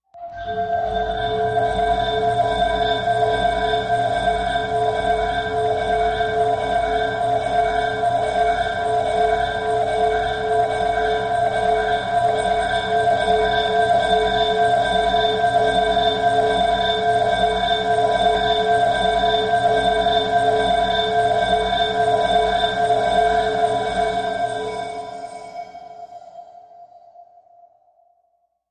Ship Shaper, Machine, Reverb Factory, Distant Alarm, Conveyer